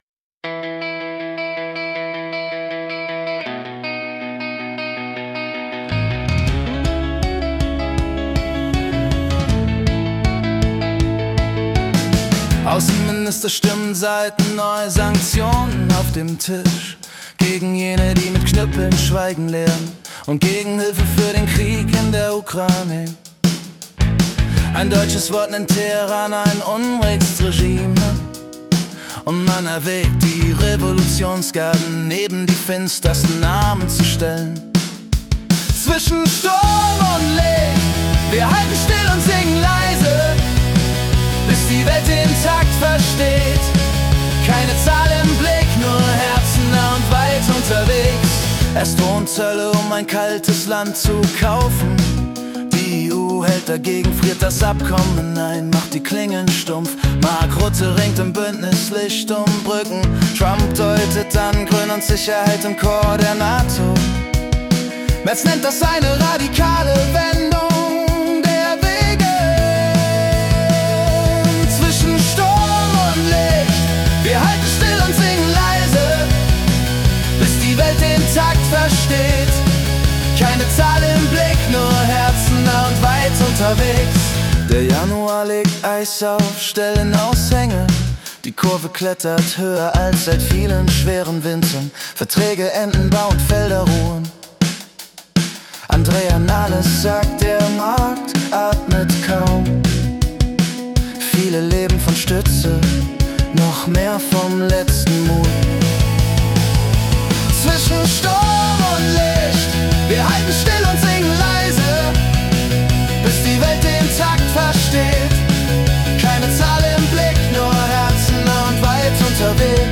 Die Nachrichten vom 31. Januar 2026 als Singer-Songwriter-Song interpretiert.
Jede Folge verwandelt die letzten 24 Stunden weltweiter Ereignisse in eine originale Singer-Songwriter-Komposition.